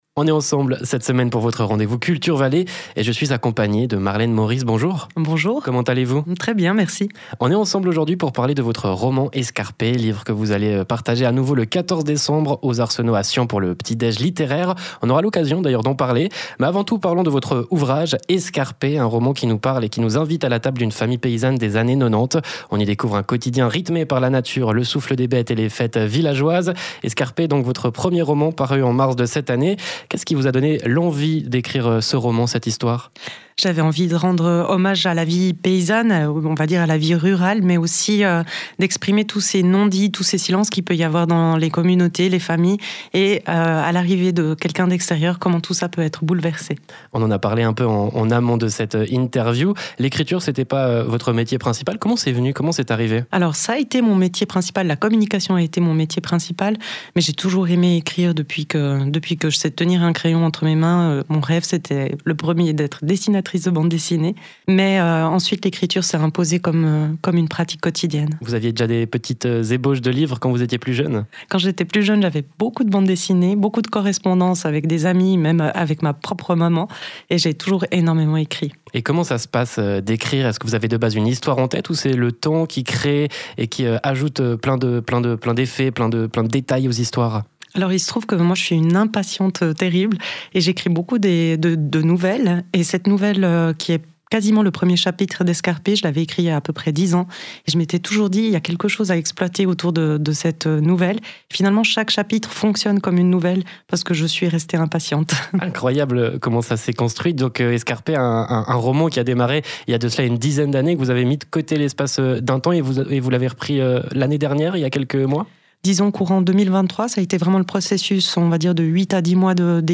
Chronique CultureValais sur Rhône FM – Emission radio